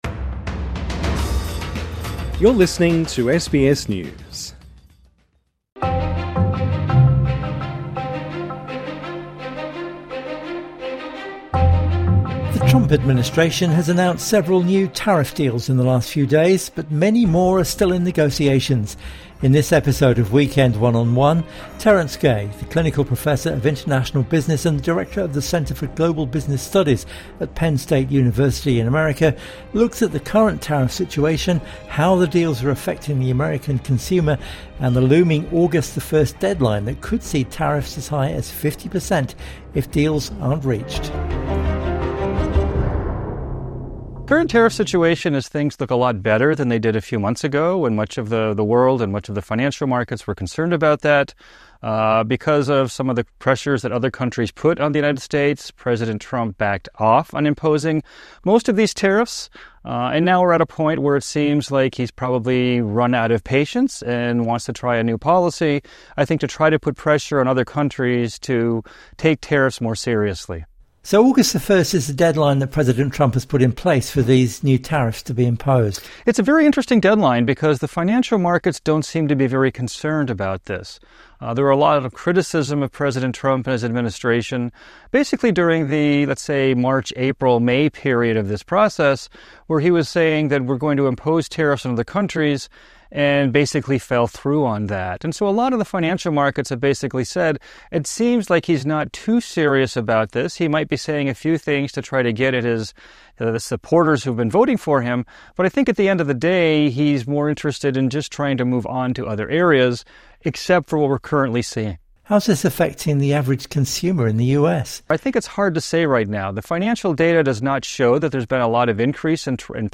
INTERVIEW: President Trump's tariffs are looming